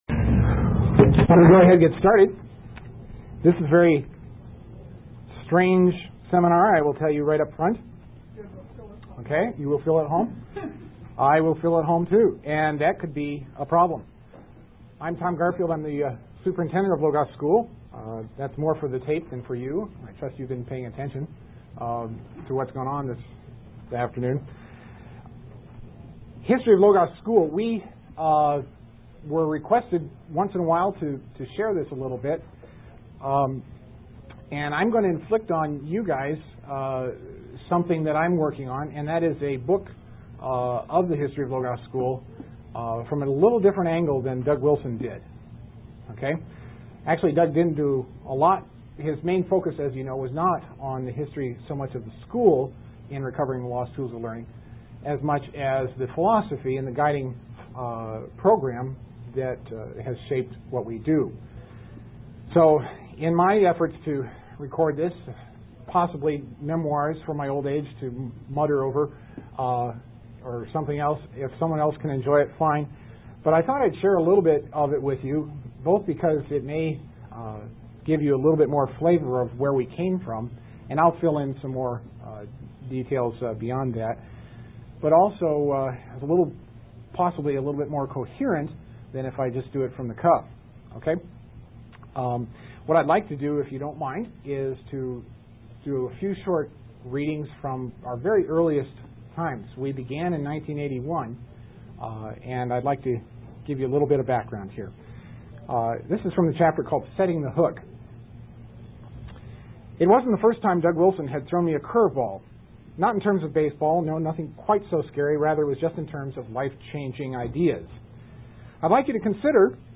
1995 Workshop Talk | 0:46:30 | All Grade Levels
Dec 20, 2018 | All Grade Levels, Conference Talks, Library, Media_Audio, Workshop Talk | 0 comments
Additional Materials The Association of Classical & Christian Schools presents Repairing the Ruins, the ACCS annual conference, copyright ACCS.